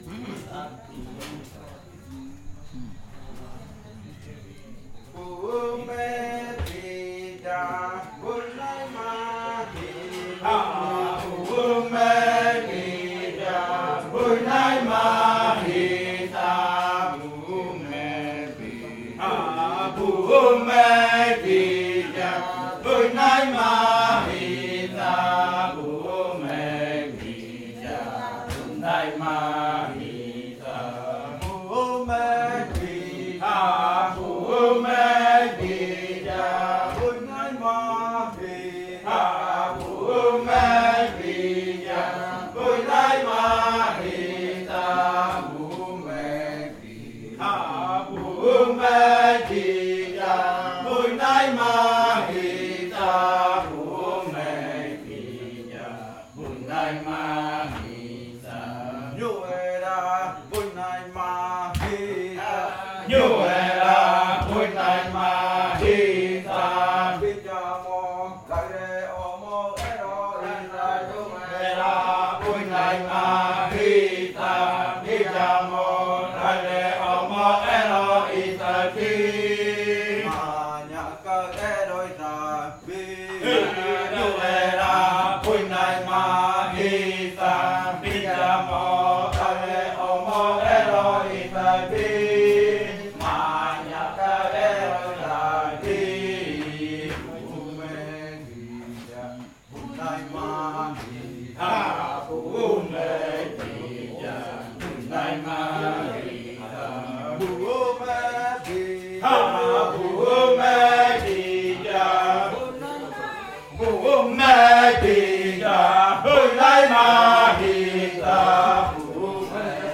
Canto de la variante muinakɨ
Leticia, Amazonas
con los cantores bailando en Nokaido.
with singers dancing in Nokaido.